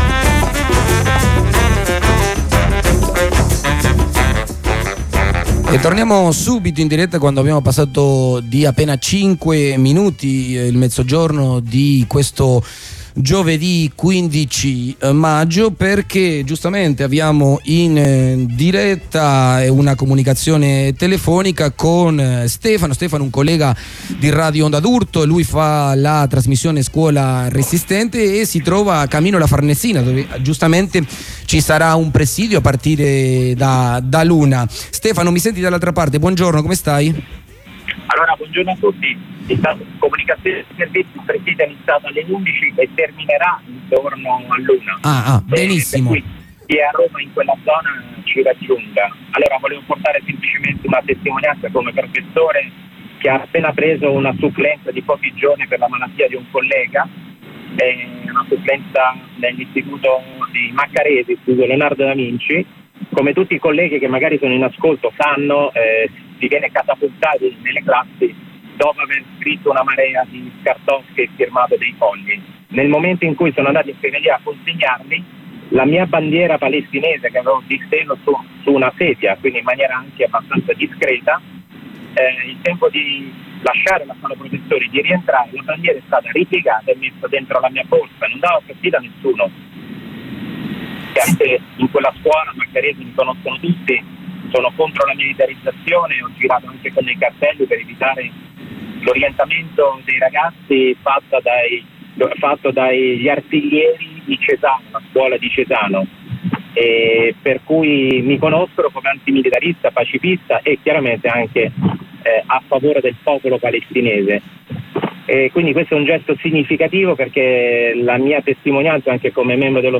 Corrispondenza